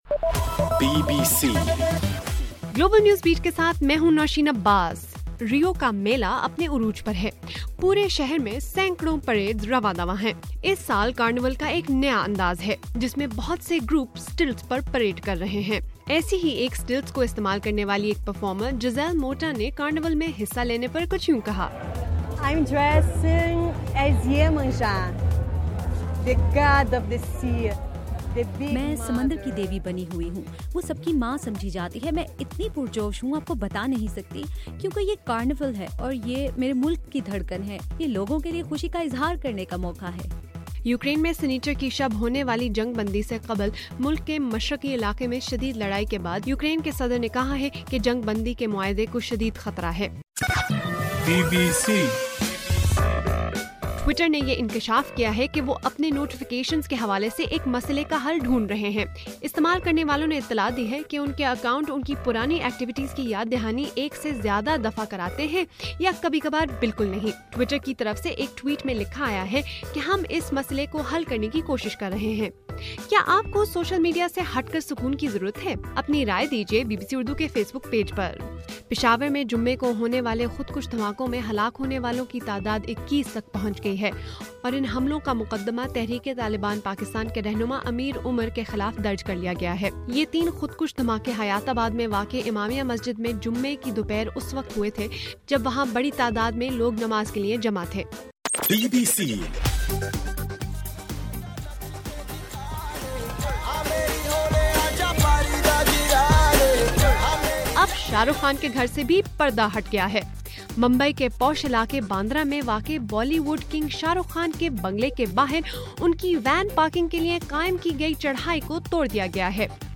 فروری 14: رات 8 بجے کا گلوبل نیوز بیٹ بُلیٹن